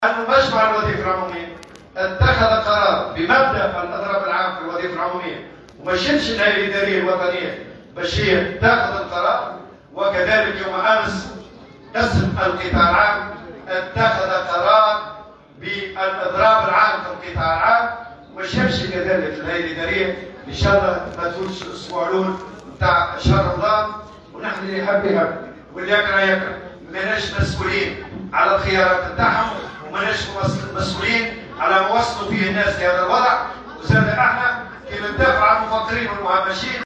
وأضاف على هامش انعقاد المؤتمر العادي للإتحاد الجهوي للشغل ببنزرت: اللي يحب يحب واللي يكره يكره.. ماناش مسؤولين على خياراتهم وعلى الوضع اللي وصلو ليه البلاد".